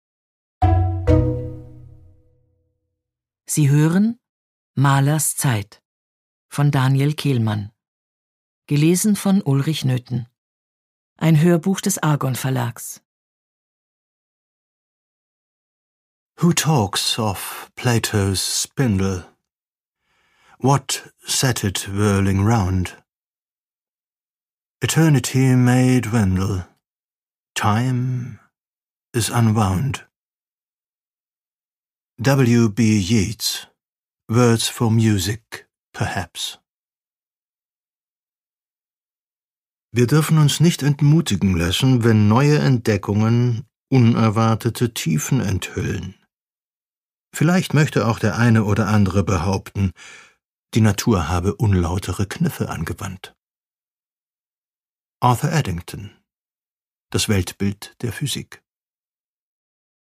Produkttyp: Hörbuch-Download
Gelesen von: Ulrich Noethen